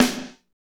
Index of /90_sSampleCDs/Northstar - Drumscapes Roland/DRM_R&B Groove/SNR_R&B Snares x